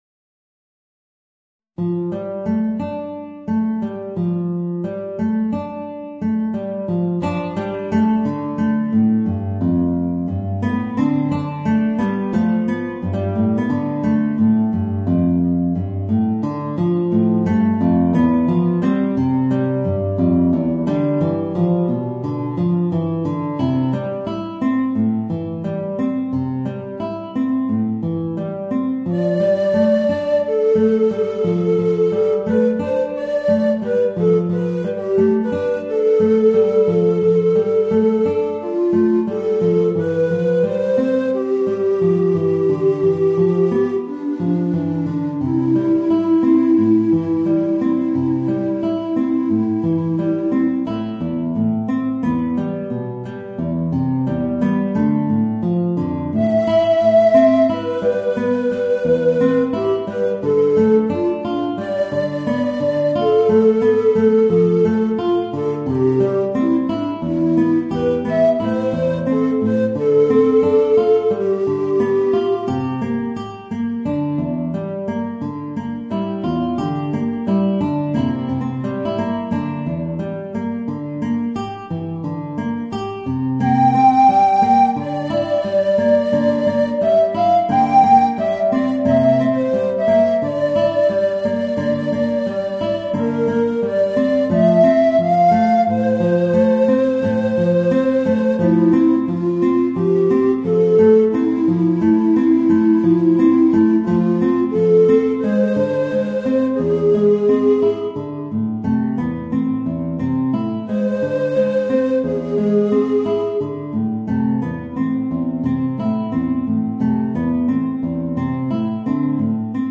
Voicing: Guitar and Tenor Recorder